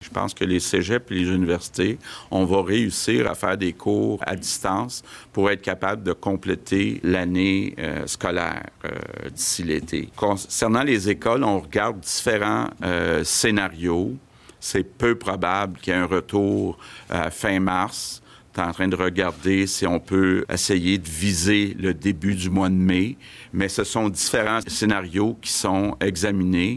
En point de presse vendredi, il évoque plutôt un retour en classe vers le début mai pour les niveaux primaire et secondaire, alors que les étudiants de niveaux collégial et universitaire devraient pouvoir terminer leur session en télé-enseignement.